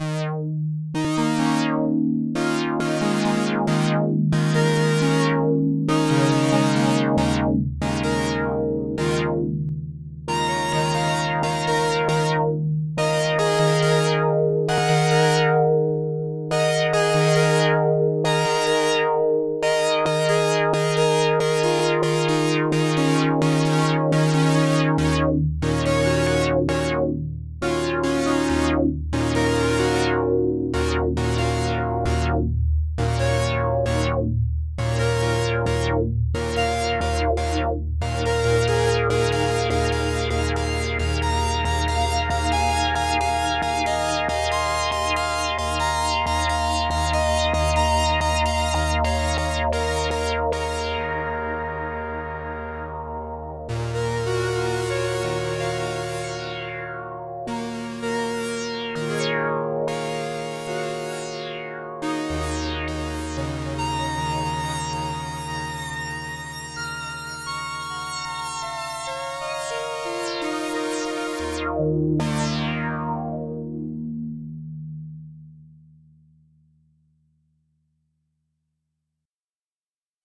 And the same idea on a synthier patch.